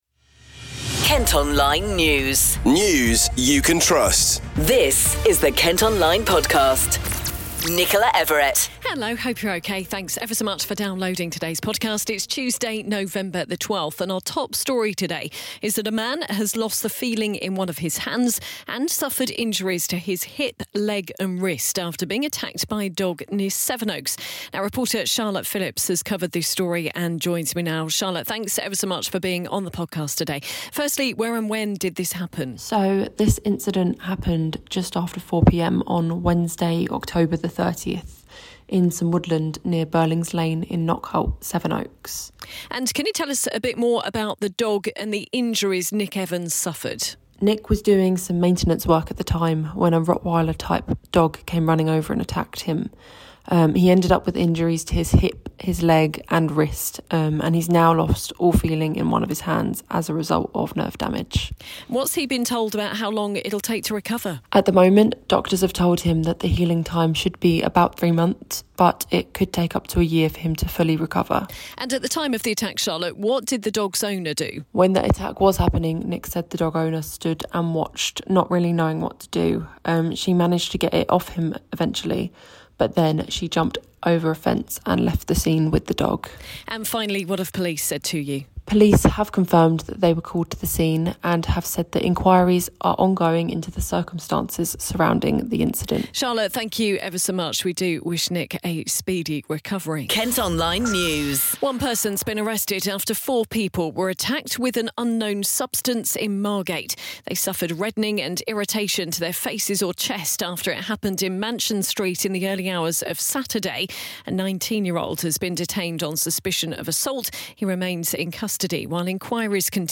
Also in today’s podcast, we can hear from the chief inspector of prisons after a report revealed how inmates at a Medway jail are using cardboard and towels to stop rats from getting into their cells.